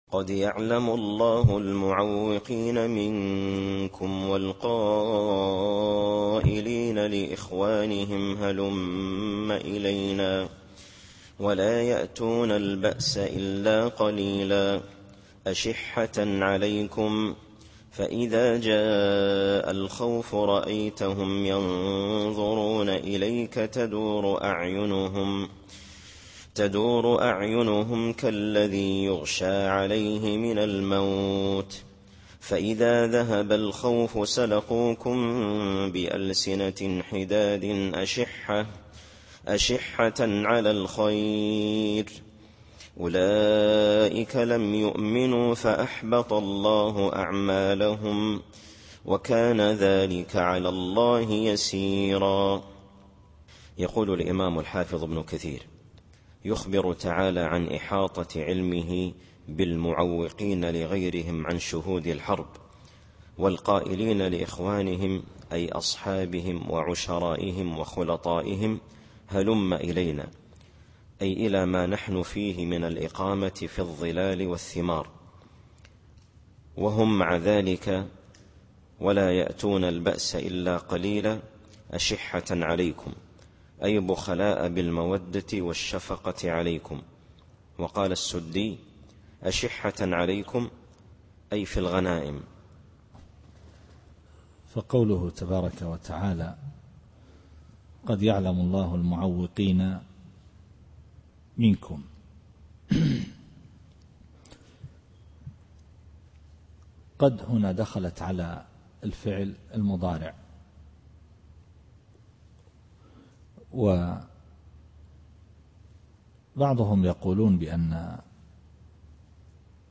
التفسير الصوتي [الأحزاب / 18]